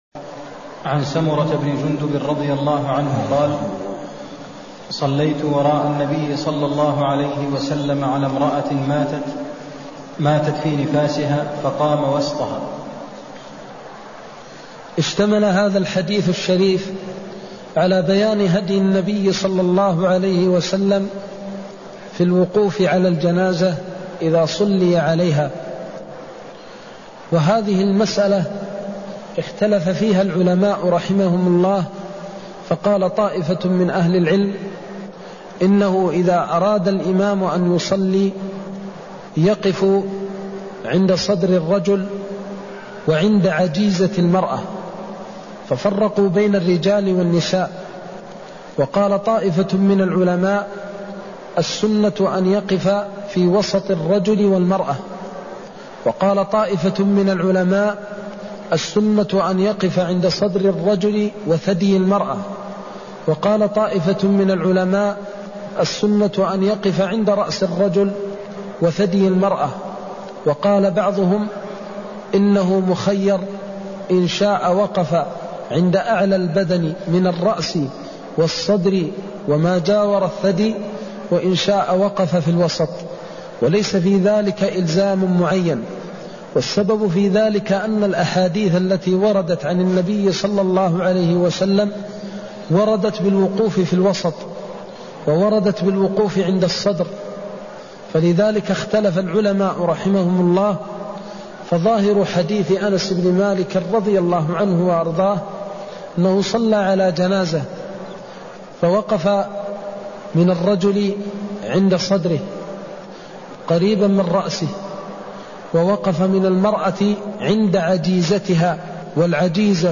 المكان: المسجد النبوي الشيخ: فضيلة الشيخ د. محمد بن محمد المختار فضيلة الشيخ د. محمد بن محمد المختار هديه في الوقوف على الميت حين الصلاة (157)